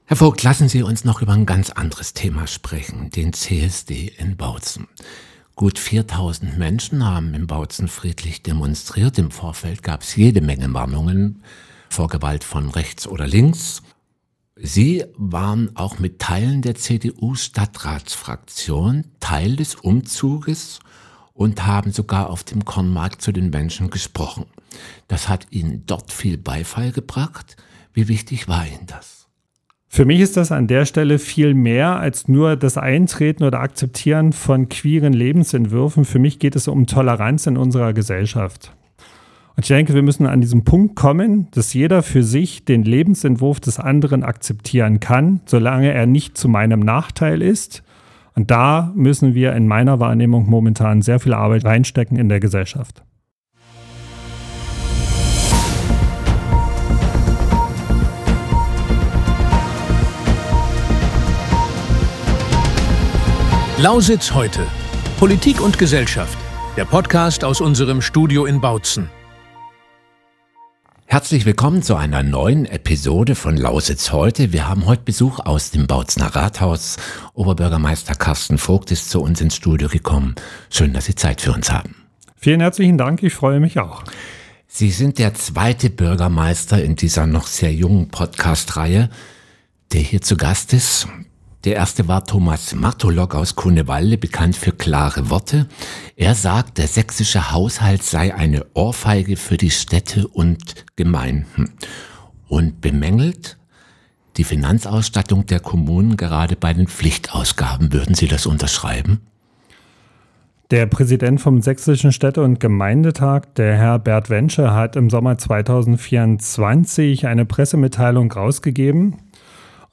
Oberbürgermeister Karsten Vogt spricht im "Lausitz heute"-Podcast offen über die finanziellen Zwänge, die die Stadt lähmen – von maroden Schulen über die Pauli-Straße bis zur Unkrautbekämpfung. Er erklärt den aktuellen, komplizierten Stand beim Mega-Projekt Spreebrücke und warnt vor einem Bürgerentscheid ohne gesicherte Finanzierung.
Ein Gespräch über die großen Baustellen und die kleinen Ärgernisse in der Spreestadt – vom "wüsten" Kornmarkt über den unschönen Kreisverkehr an der Schliebenstraße bis zur immer noch nicht elektrifizierten Bahnstrecke zwischen Görlitz und Dresden, die seit Jahrzehnten auf sich warten lässt.